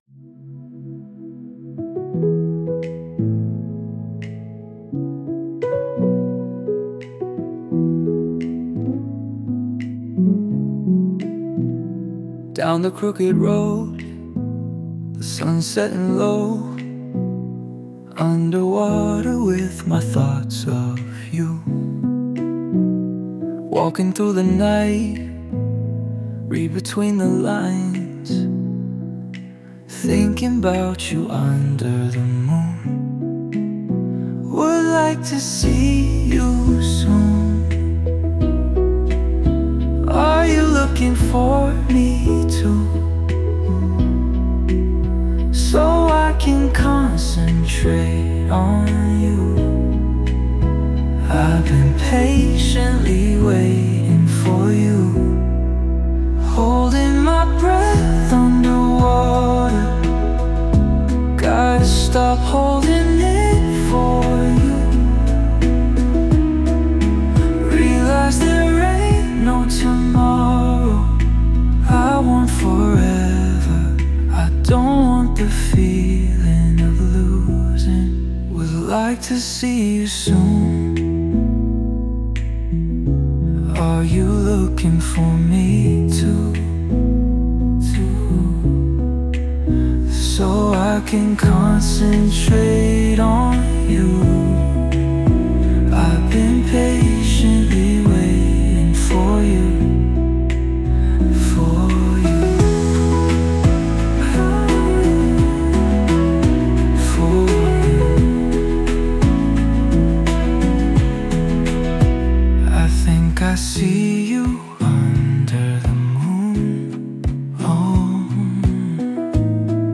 📝 “A slow acoustic song with a calm male voice, singing about lost love under the moonlight. The melody should be soft, emotional, and relaxing.”
✔ Style Tags: Slow, Acoustic, Emotional, Relaxing, Soft